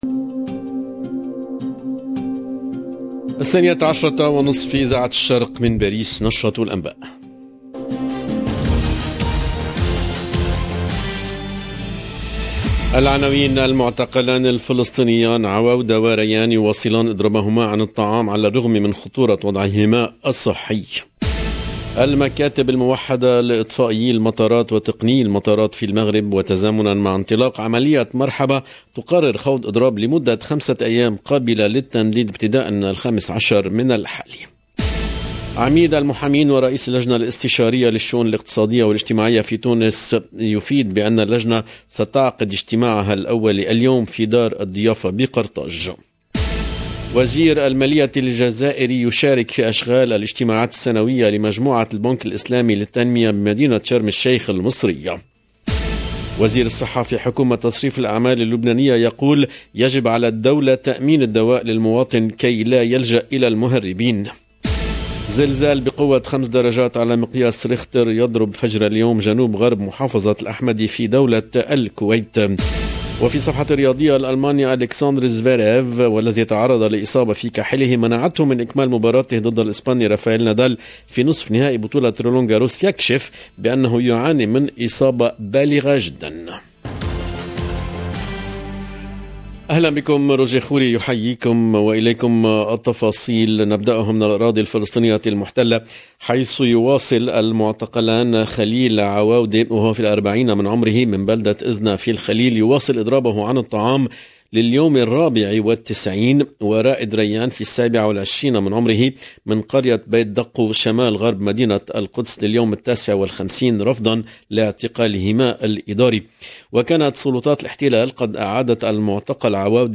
LE JOURNAL EN LANGUE ARABE DE MIDI 30 DU 4/06/22